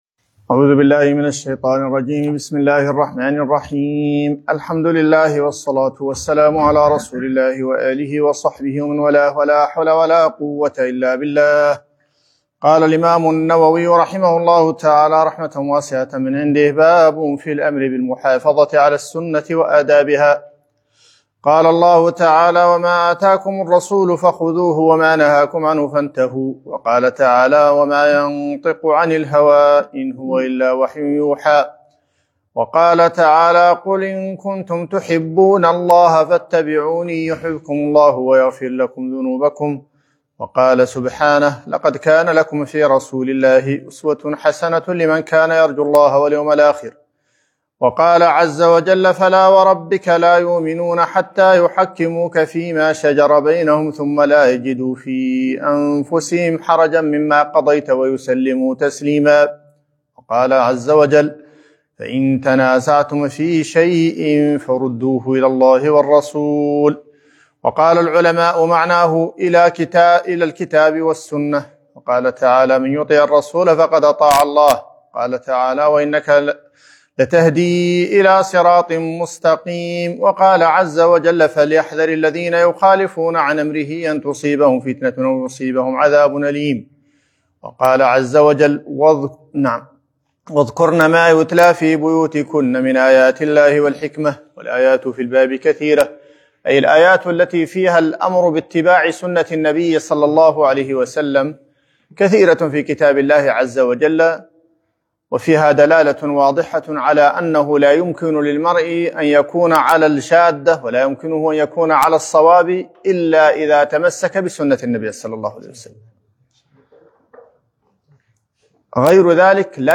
رياض الصالحين الدرس 13